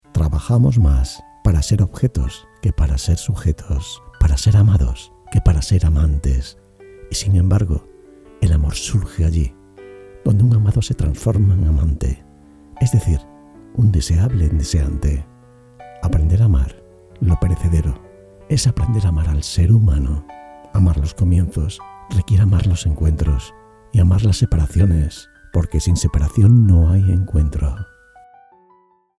grabación para simulación de aplicación